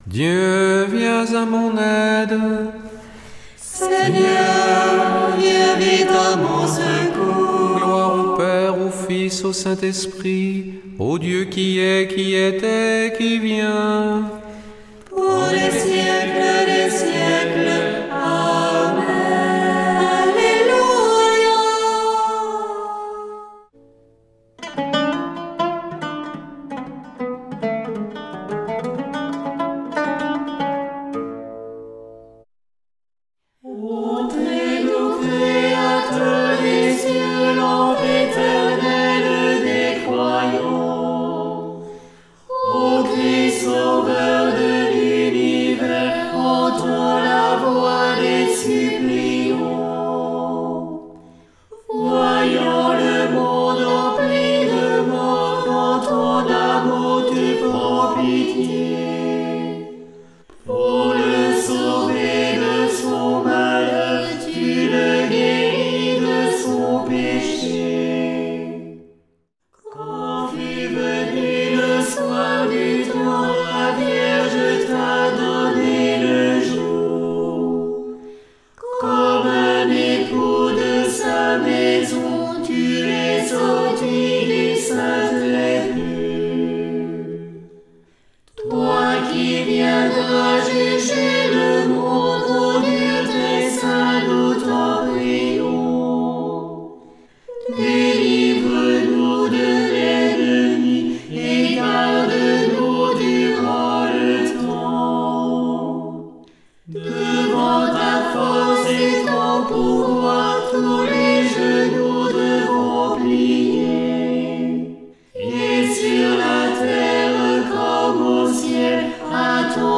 Prière du soir
Une émission présentée par Groupes de prière